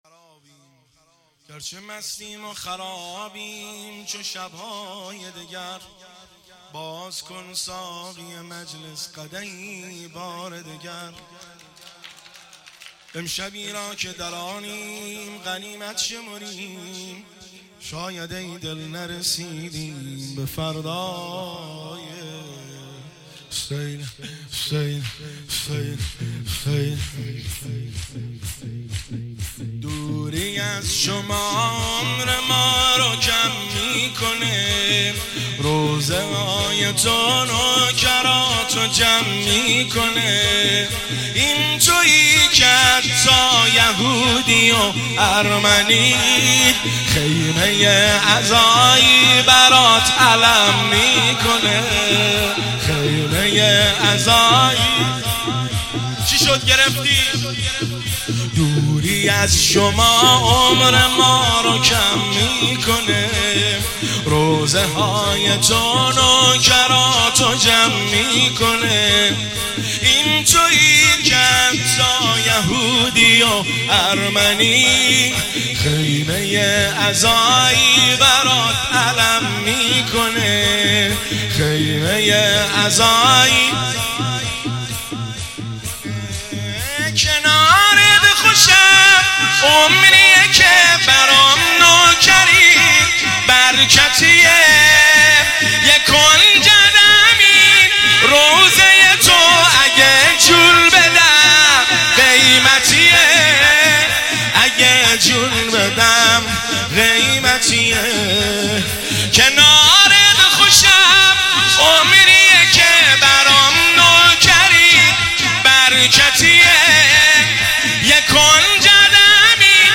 شور – شب اول شهادت امام موسی بن جعفر (ع) 1400